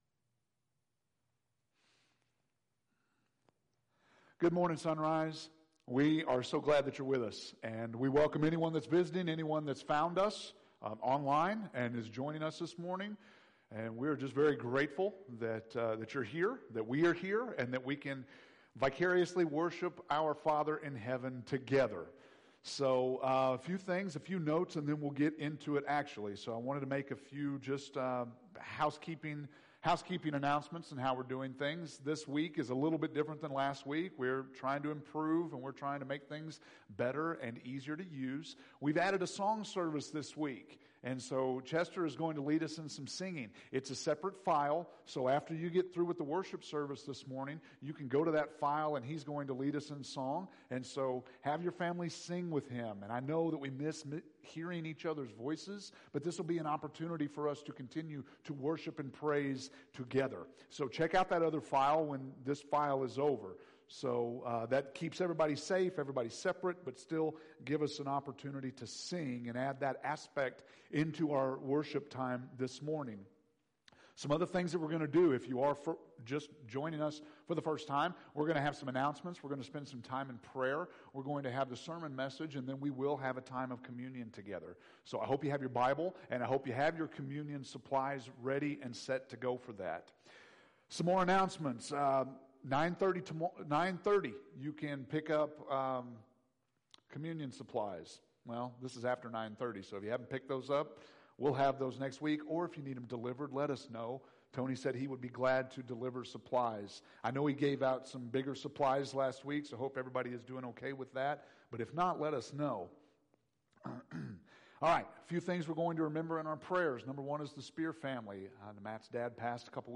April 5th – Sermons